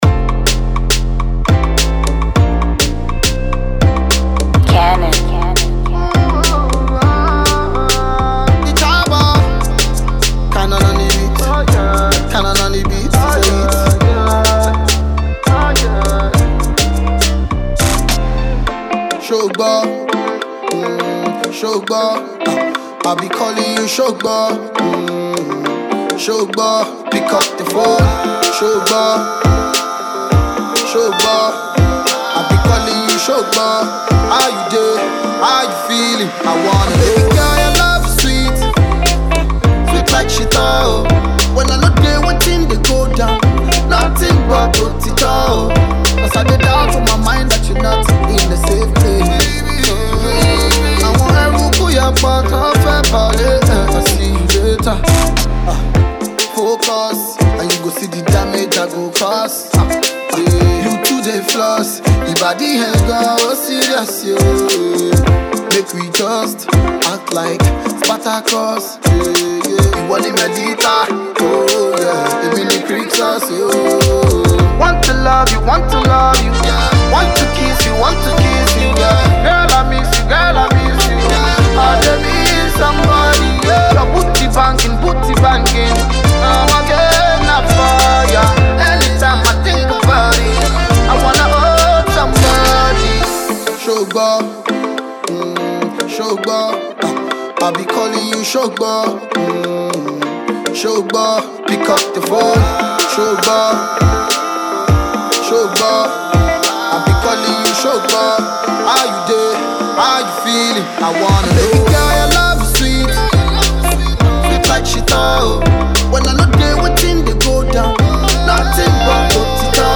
Afro-pop
chill-guitar filled record